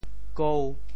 潮州府城POJ kôu 国际音标 [kou]